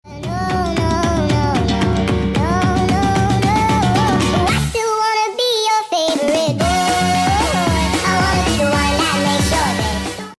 hahahaha mabaliw na sound effects free download